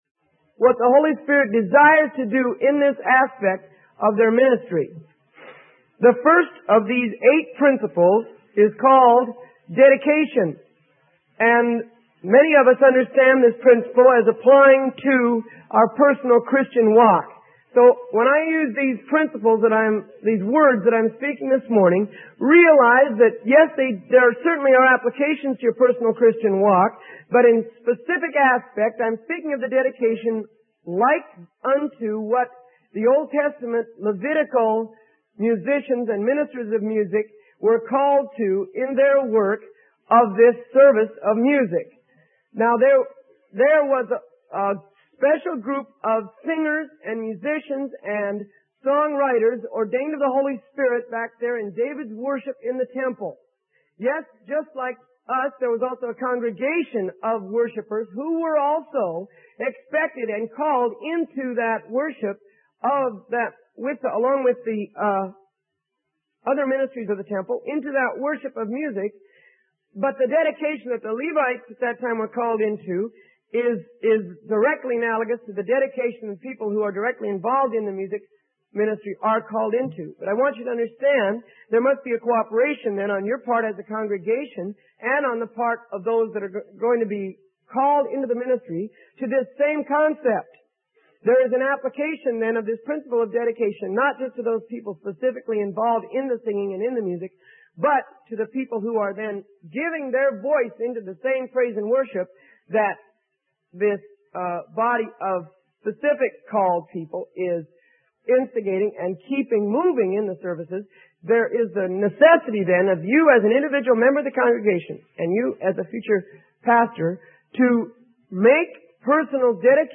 Sermon: Principles of Music Ministry Applied to the Congregation - Freely Given Online Library